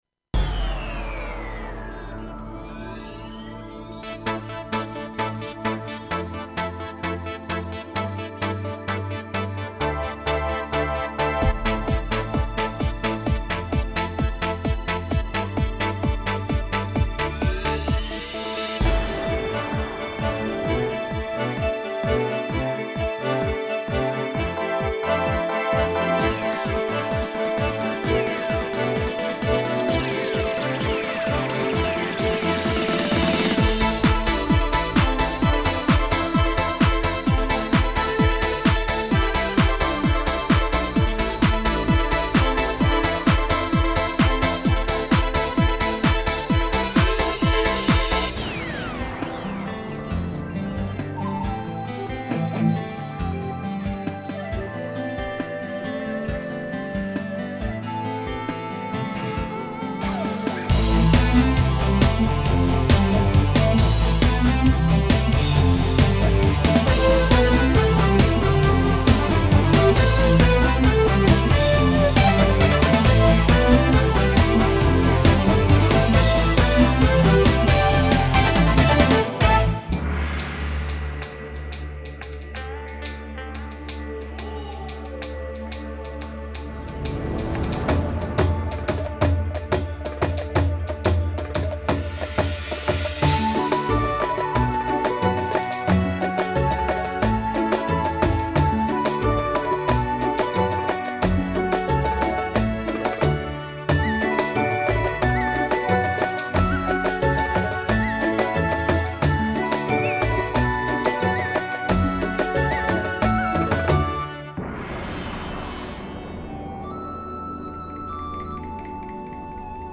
Прилагаю так же звучание некоторых патчей и фабричное демо.